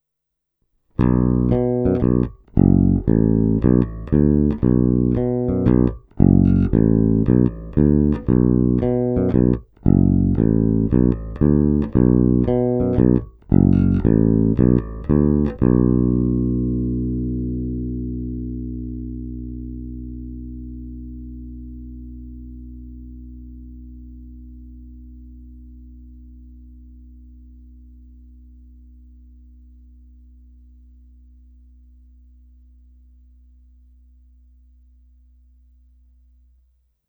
Není-li uvedeno jinak, následující ukázky jsou provedeny rovnou do zvukové karty a jen normalizovány.
Snímač u kobylky